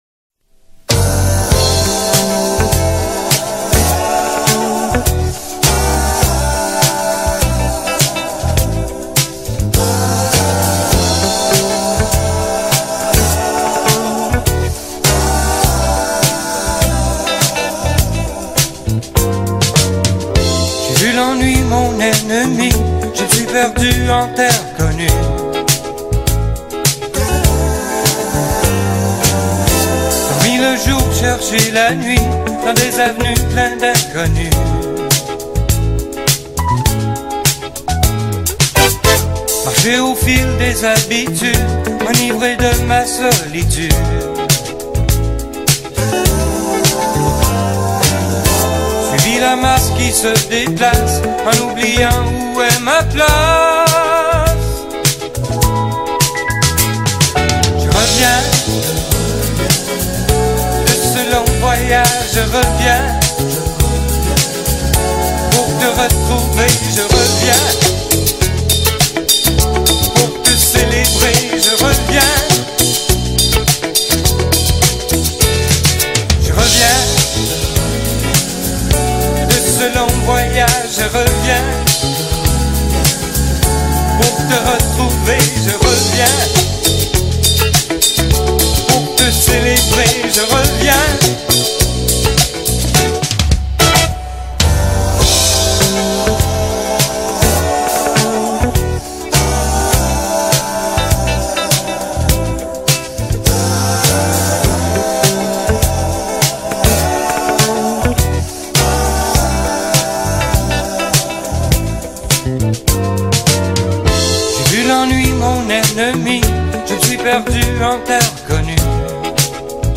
Behind the mellow vibes
guitarist
synth